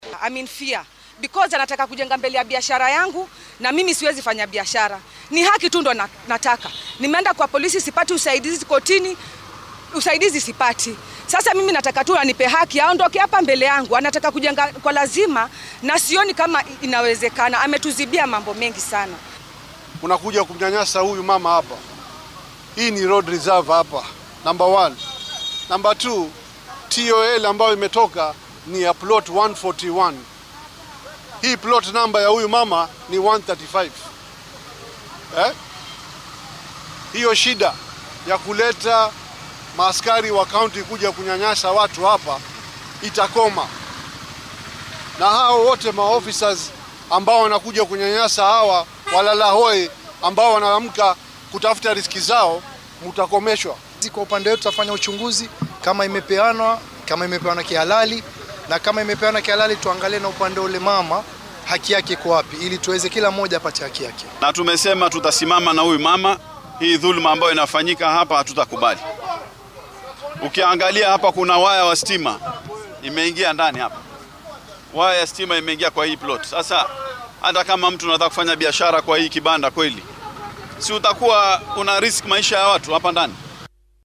Waxaa lagu soo warramayaa in la dumiyay maqaaxi laga dhisay dhul dowladeed oo ku yaalla aagga Transformer ee xaafadda Tudor ee ismaamulka Mombasa. Waxaa arrintan ka hadlay qaar ka mid ah shacabka xaafaddaasi iyo mas’uuliyiin uu ka mid yahay guddoomiyaha golaha hoose ee dowlad deegaanka Mombasa Aharub Khatri.